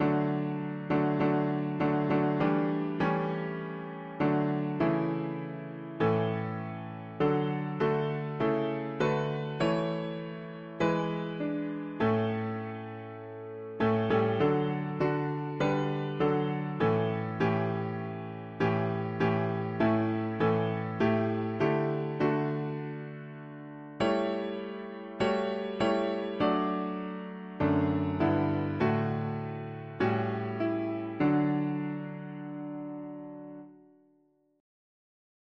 Glo… english spanish christian 4part
Key: D major Meter: 7.10.8.7.8